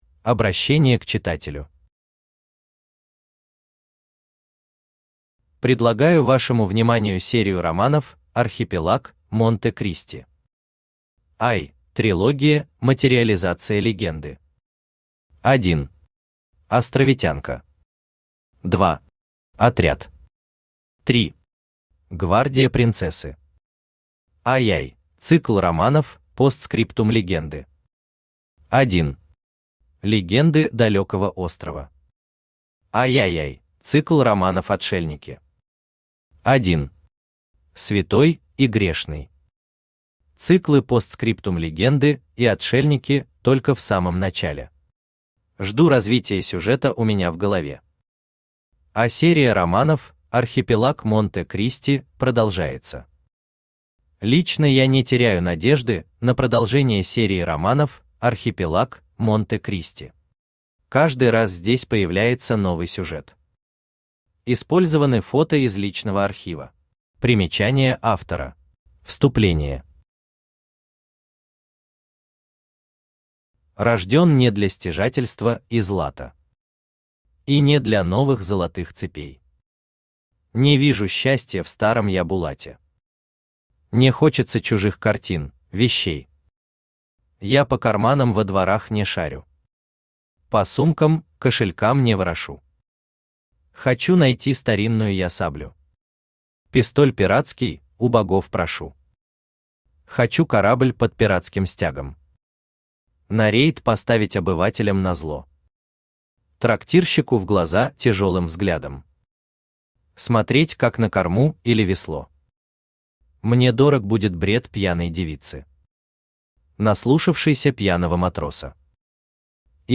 Аудиокнига Святой и грешный. Цикл «Отшельники». Том 1 | Библиотека аудиокниг
Том 1 Автор Геннадий Анатольевич Бурлаков Читает аудиокнигу Авточтец ЛитРес.